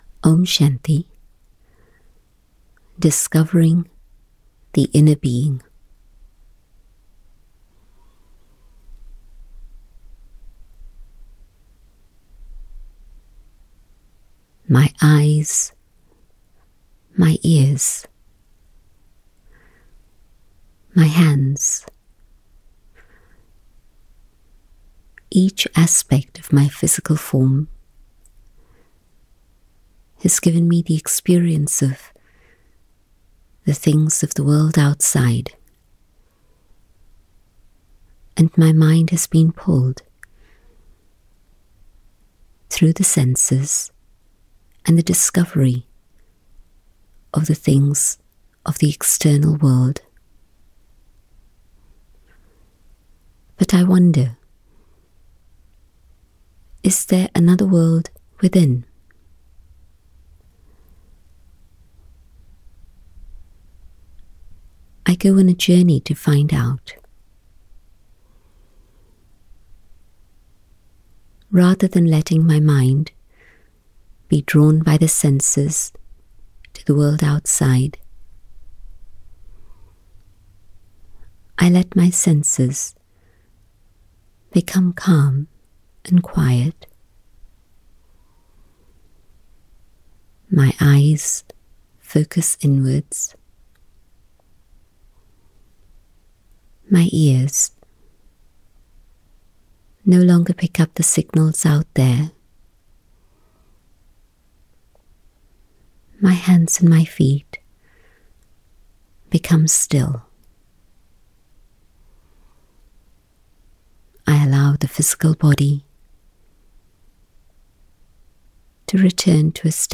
Downloadable Meditations